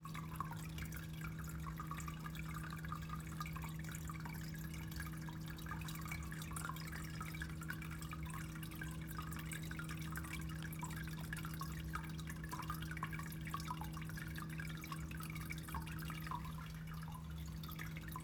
Cat water fountain.wav